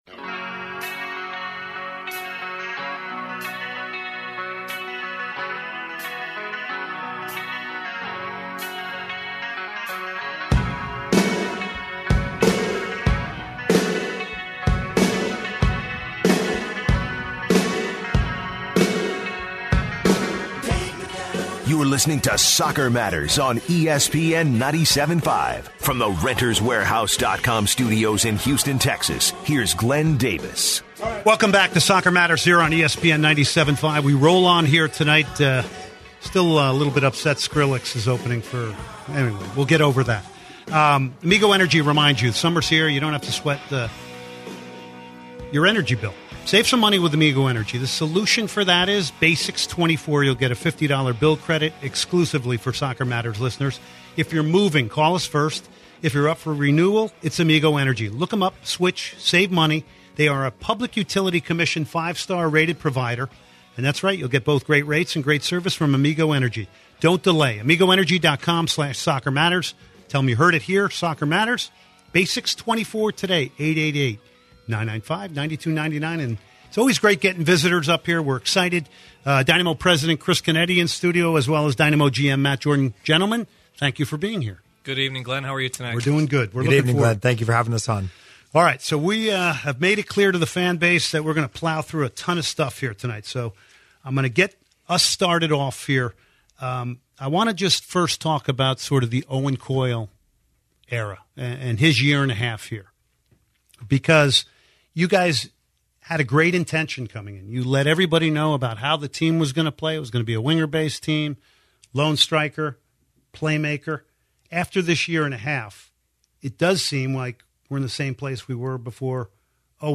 in-depth discussion